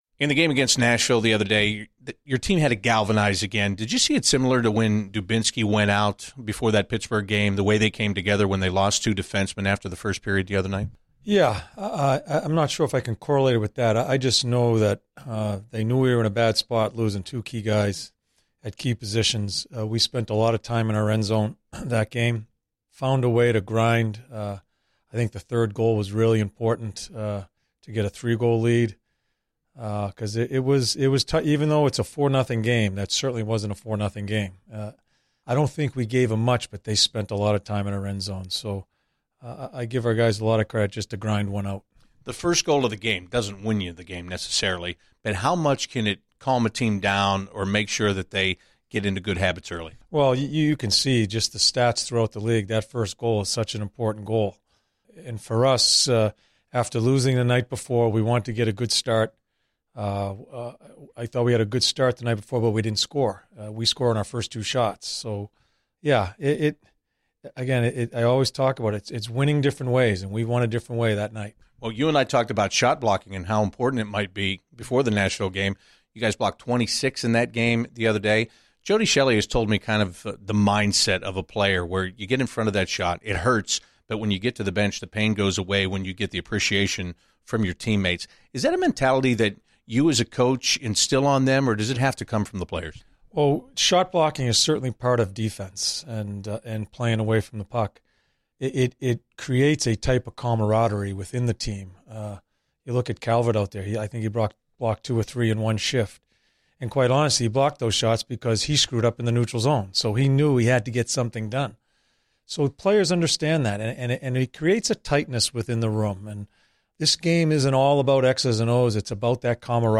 John Tortorella Pre-Game 11/22/15